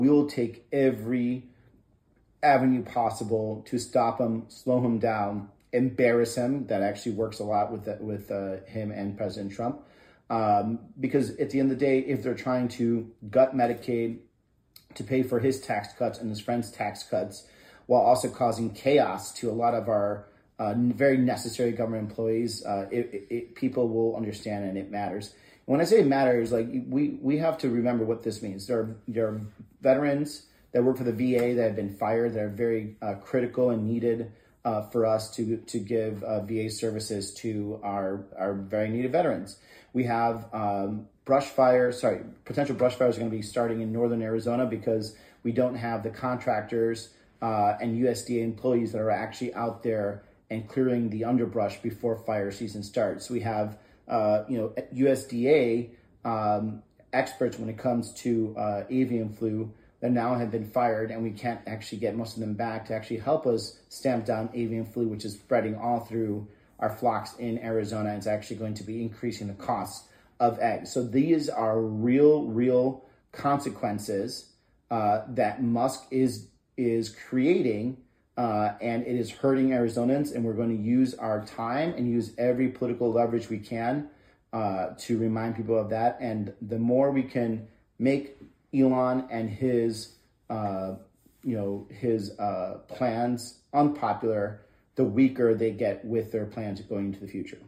PHOENIX – Last night, Senator Ruben Gallego (D-AZ) spoke to over 3,000 Arizonans during his first telephone town hall.